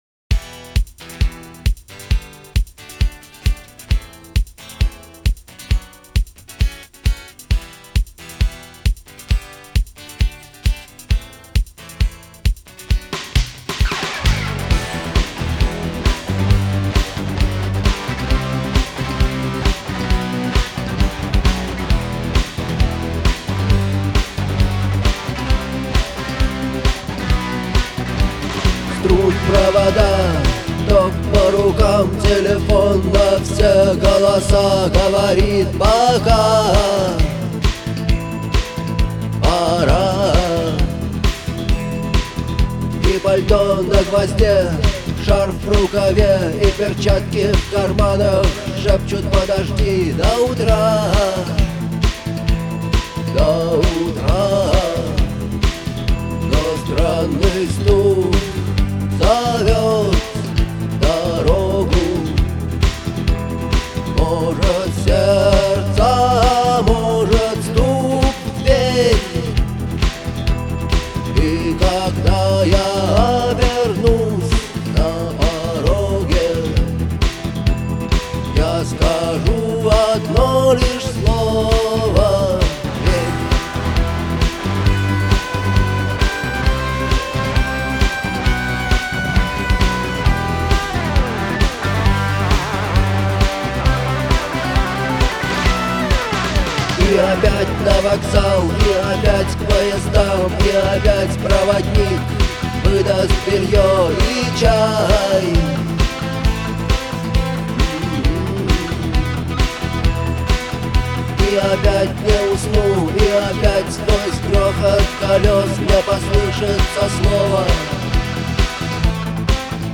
это яркий пример постпанкового звучания